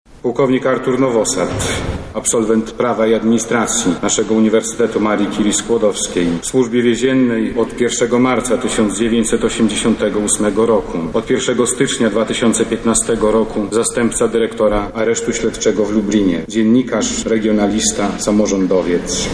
Uroczystej mszy pogrzebowej w Archikatedrze Lubelskiej przewodniczył biskup Ryszard Karpiński. Odbyła się ona zgodnie z ceremoniałem Służby Więziennej.
pogrzeb-funkcjonariuszy-3.mp3